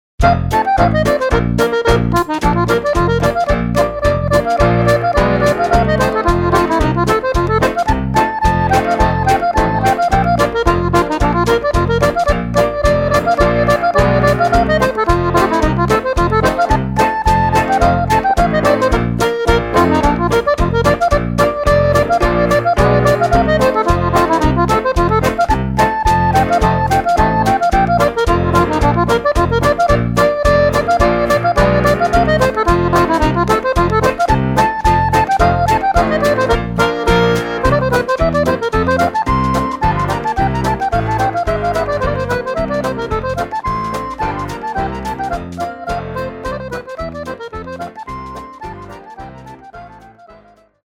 Music for Scottish Country and Old Tyme Dancing